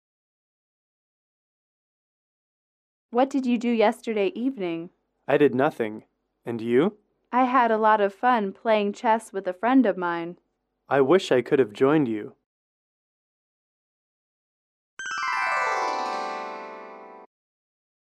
英语口语情景短对话50-1：晚上的娱乐(MP3)